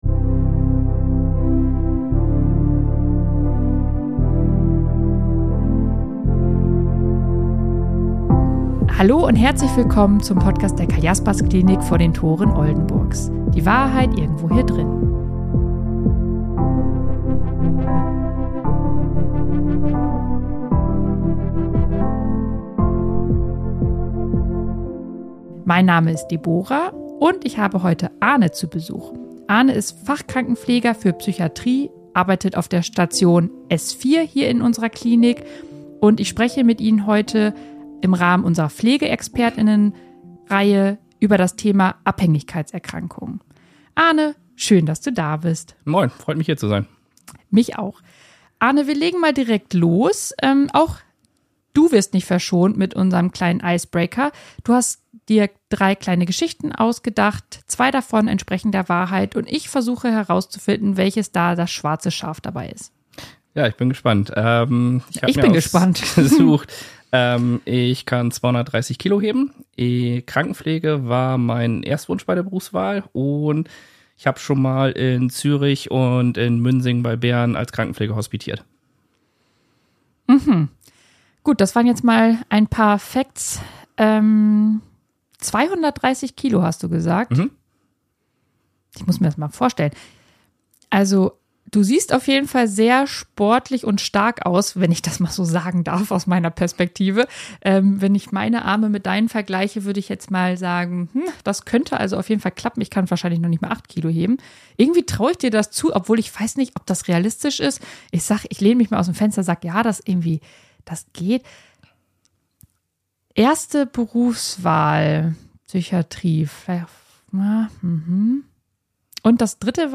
#44 ABHÄNGIGKEITSERKRANKUNGEN Experten-Talk ~ Die Wahrheit Irgendwo Hier Drinnen Podcast